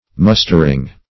Mustering - definition of Mustering - synonyms, pronunciation, spelling from Free Dictionary
mustering.mp3